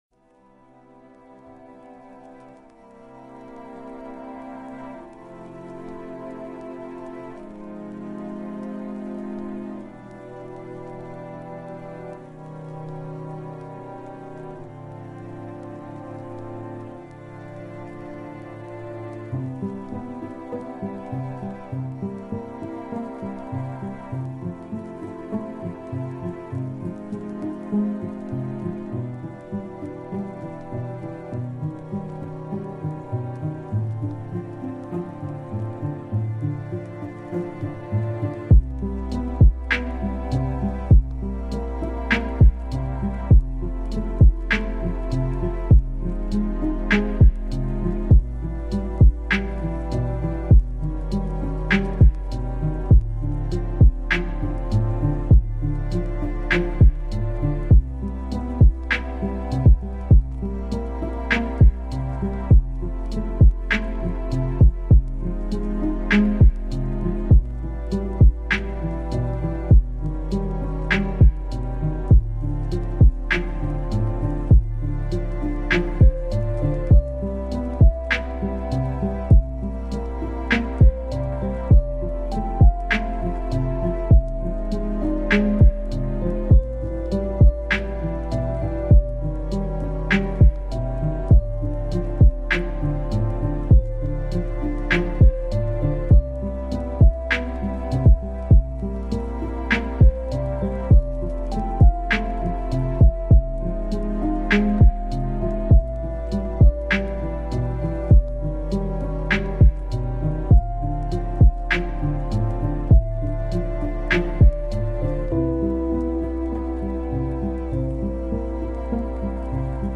Chopin : Piano Pour Lecture et Étude